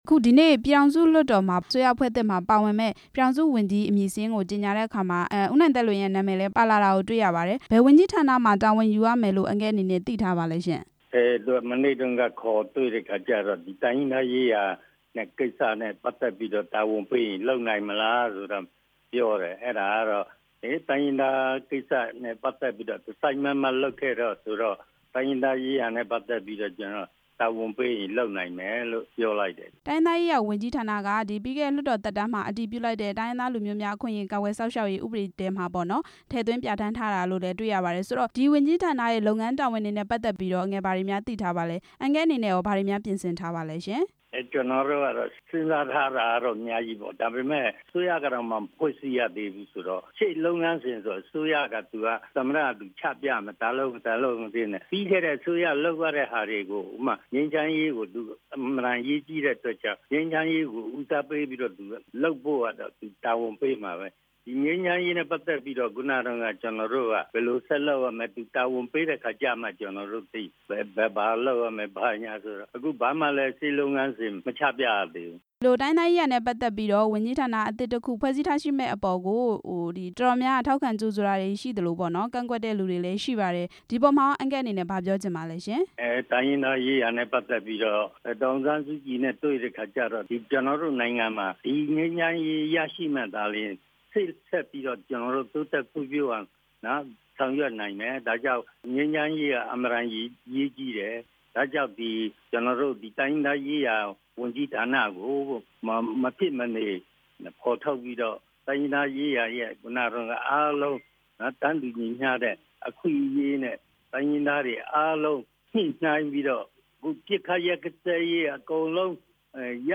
တိုင်းရင်းသားရေးရာဝန်ကြီး ဖြစ်လာမယ့် ဦးနိုင်သက်လွင် ကို မေးမြန်းချက်